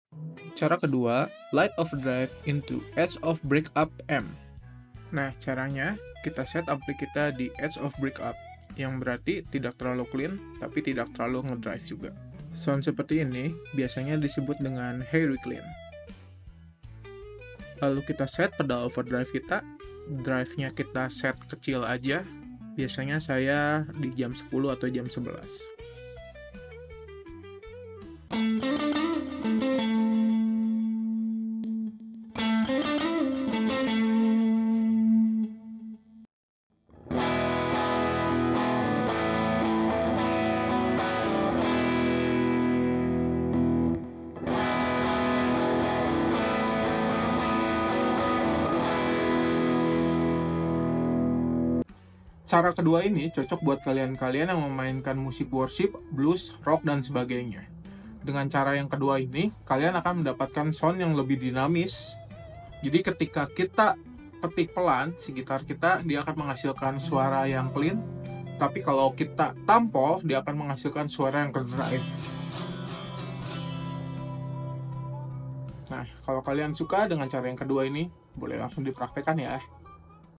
Dengan menggunakan cara ini kita akan mendapatkan sound yg disebut “hairy clean” nahhh sound ini lah yg ENAK SEKALI, jadi kalau dipetik pelan akan clean, tapi kalo kita tanpol, nahhh soundnya akan drive!!! Cara mendapatkan sound seperti ini adalah denngan cara setting ampli kita di edge pf breakup, biasanya di antara jam 6-7 tuh, tergantung ampli / mulltiefek / pedal amp kita. Sedangkan pada pedal overdrive kita set drive nya kecil aja, sampe mulai muncul suara drive dari pedall kita, biasa sih saya antara jam 10-11, mentok di jam 12 untuk settingan knob drive nya.